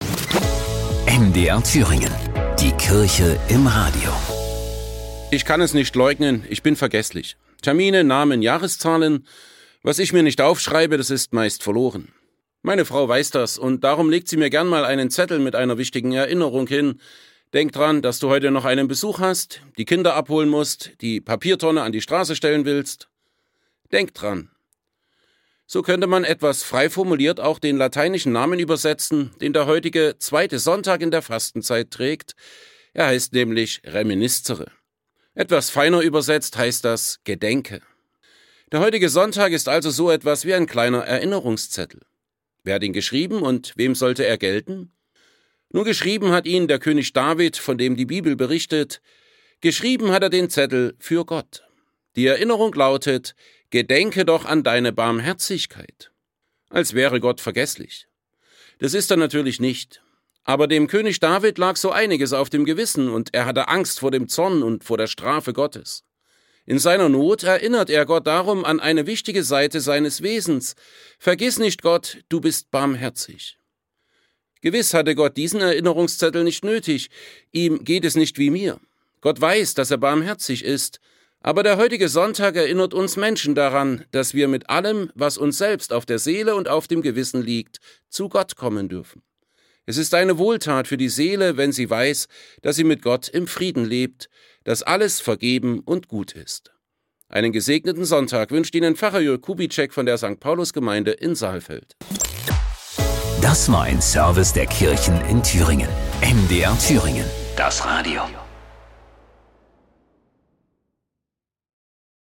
Serie: Radioandachten